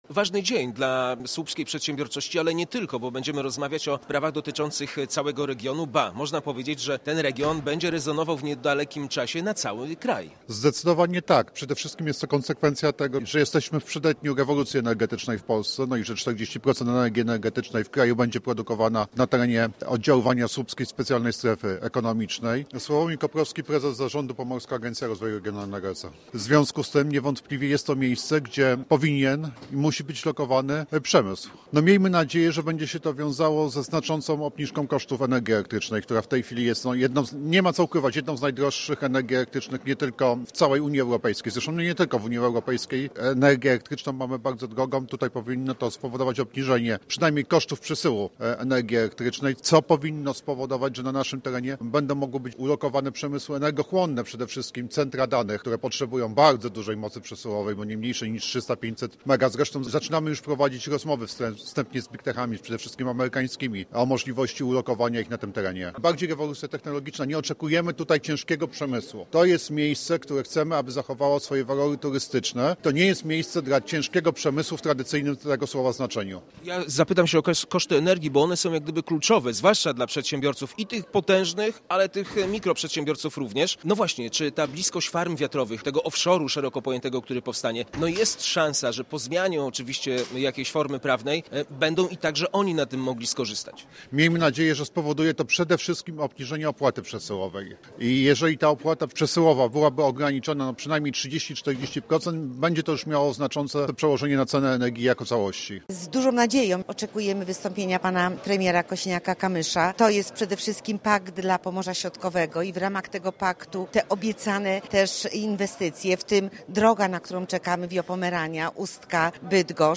Polska wspólnie z Norwegią są autorami architektury bezpieczeństwa Bałtyku, której budowa to dziś jedno z kluczowych zadań Sojuszu Północnoatlantyckiego – powiedział w czwartek w Słupsku wicepremier, minister obrony narodowej Władysław Kosiniak-Kamysz.